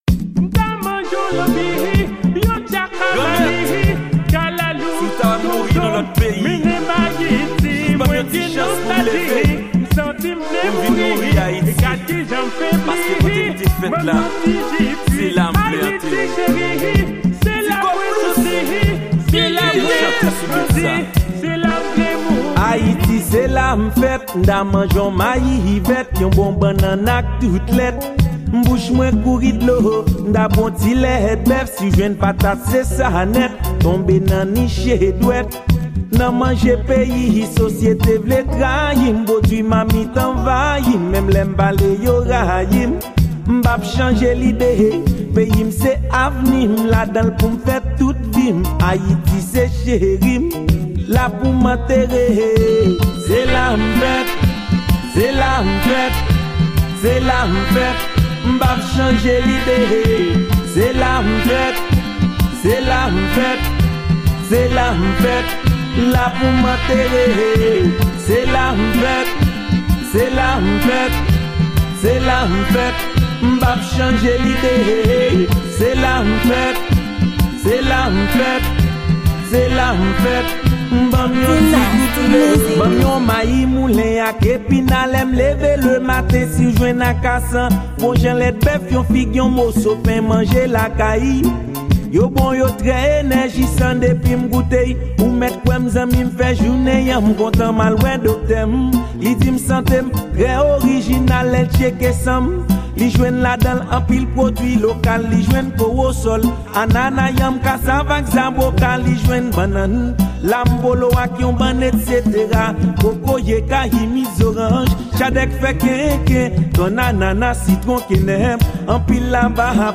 Genre: Word.